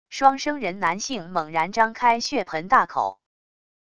双生人男性猛然张开血盆大口wav音频